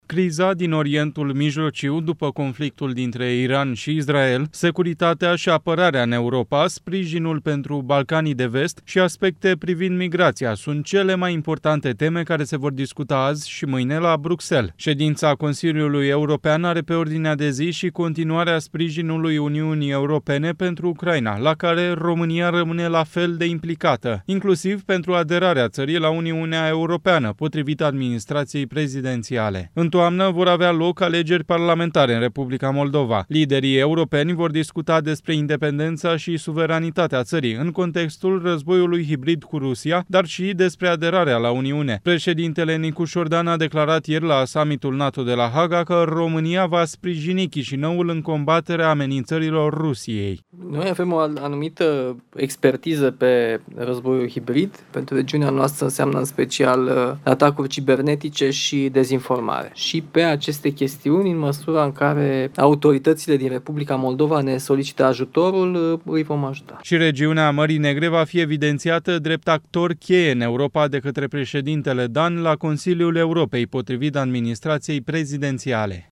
Președintele Nicușor Dan a declarat ieri la Summitul NATO de la Haga că România va sprijini  Chișinăul în combaterea amenințărilor Rusiei: